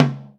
Tom 09.wav